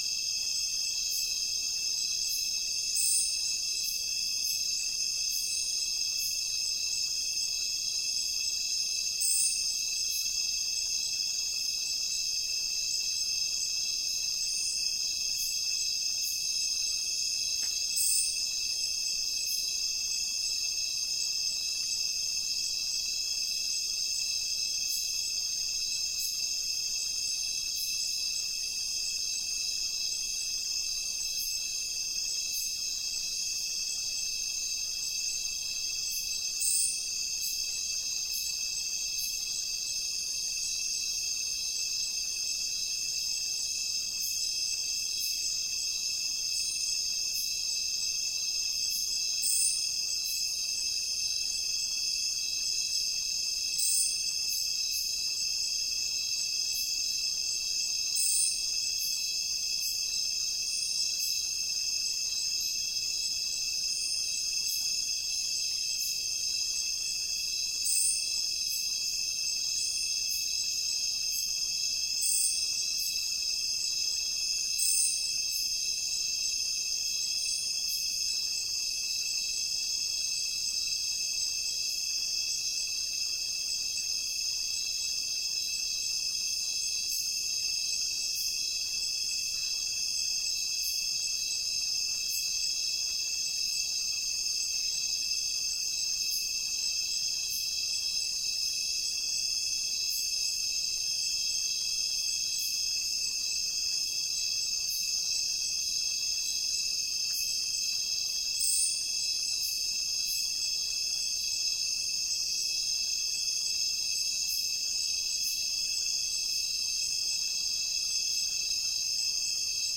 Demonstration soundscapes
biophony